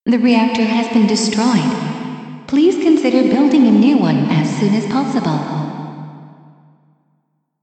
(The reverb and such will already be added to the samples and I will make sure it is as less seconds as possible while still keeping it understandable.)
I just found a pretty nice text-to-speech that has a really great voice that sounds perfect for broadcast-like recording :smiley:
What it gives so far with a Female voice.